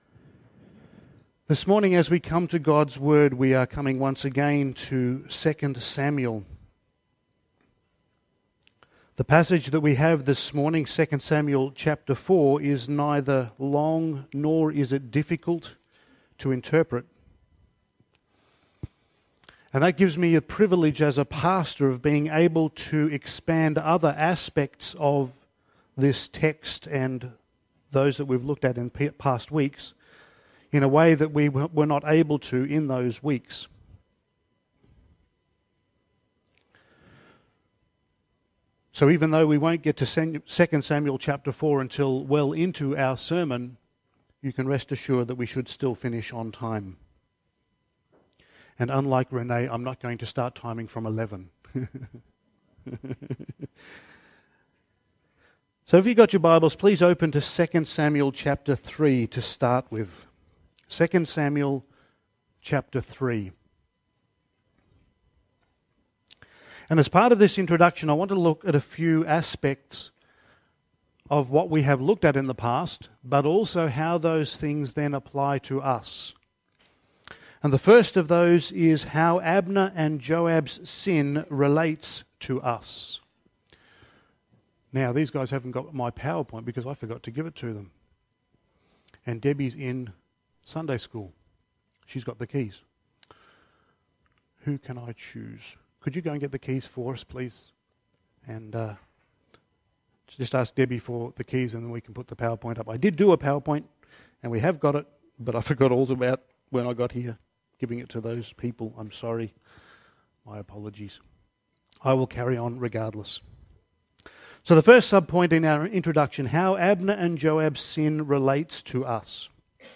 Passage: 2 Samuel 4:1-12 Service Type: Sunday Morning